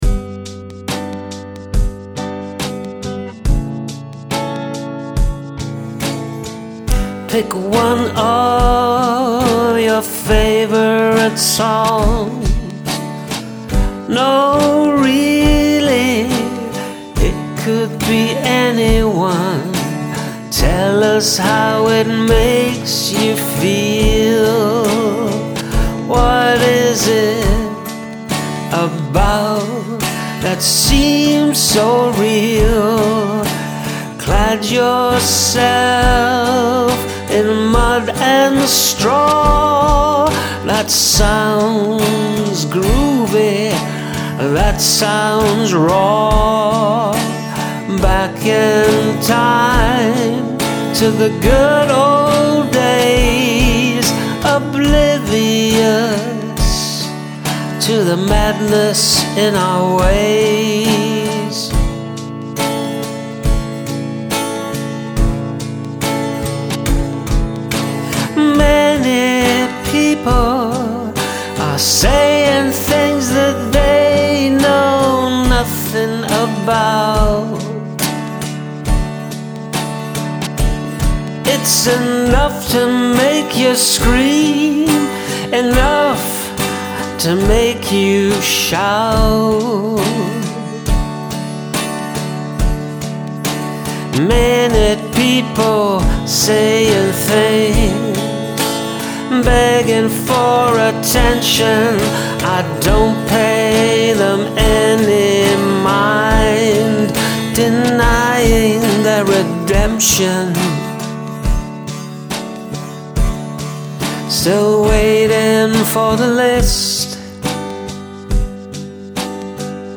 Enjoyable mellow patient energy to this 'waiting for the list' song.
Something really pleasing about the steadiness of the percussion. Good and poppy and chill.
Love the chilled out vibe of this one!